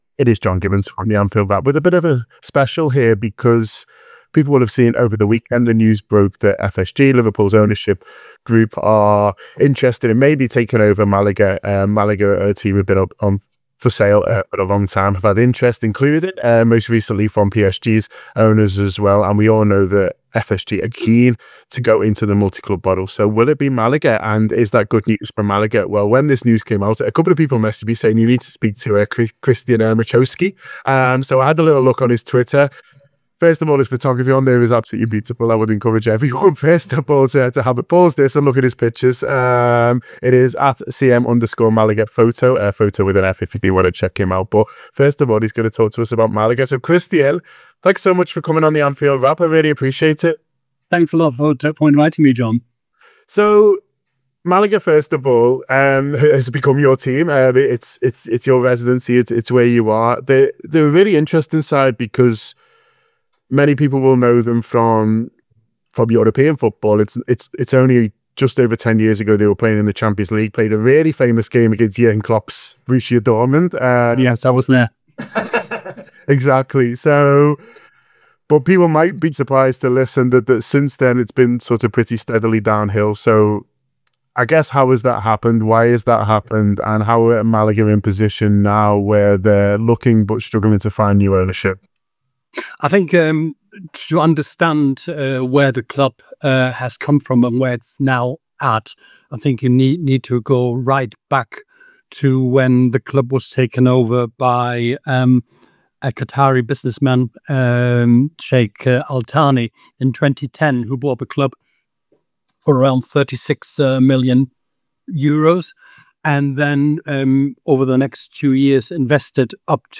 Below is a clip from the show – subscribe for more on FSG’s rumoured interest in Malaga..